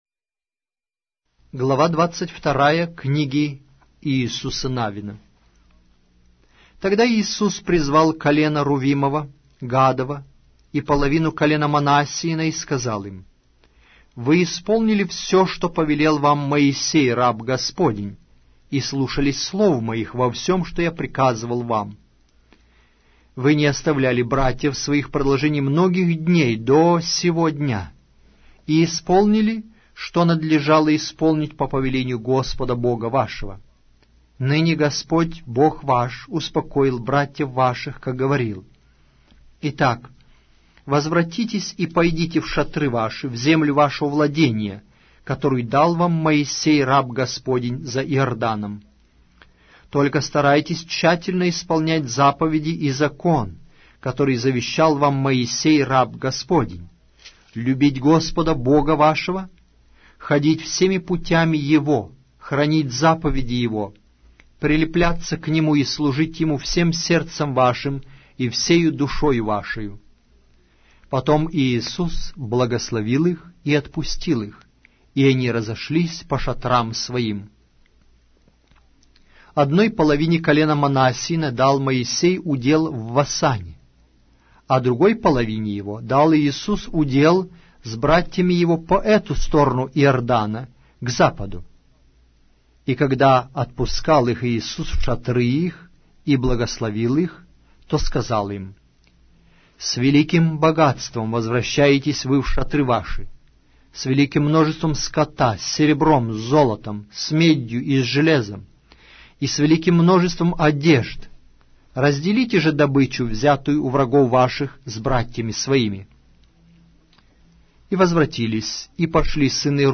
Автор аудиокниги: Аудио - Библия
Аудиокнига: Иисус Навин